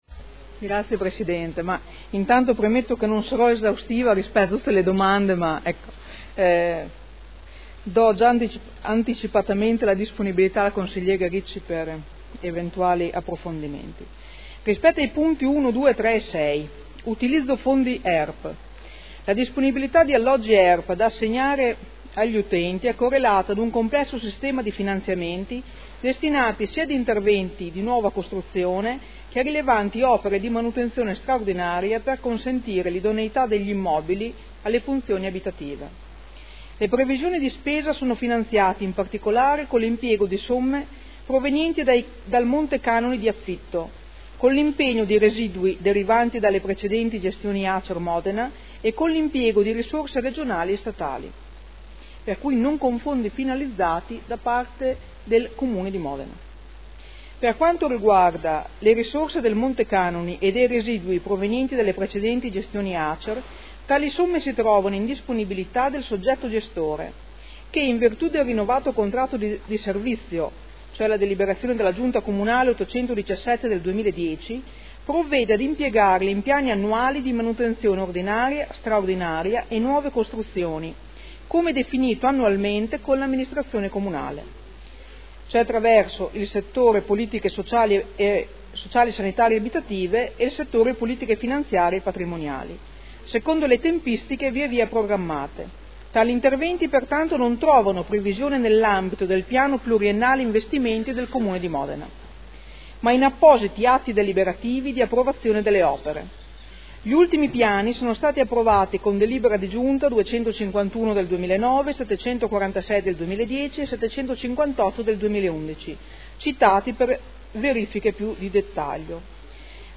Seduta del 14/05/2012. Risponde a interrogazione del consigliere Ricci (Sinistra per Modena) avente per oggetto: Definizione del Bilancio preventivo 2012, risorse per la realizzazione di alloggi ERP”